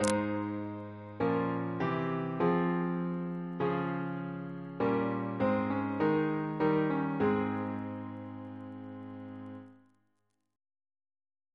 Single chant in A♭ Composer: Maurice Greene (1695-1755) Reference psalters: ACB: 245